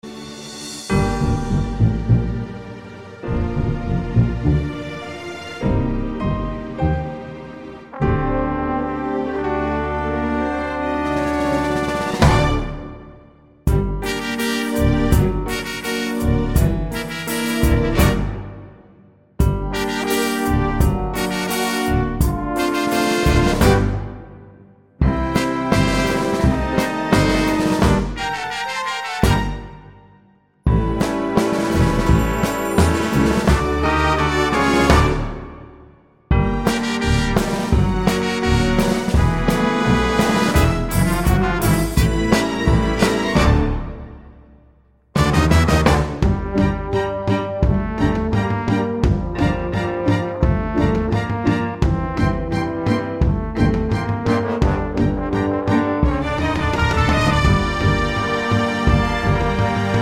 no Backing Vocals Soundtracks 3:45 Buy £1.50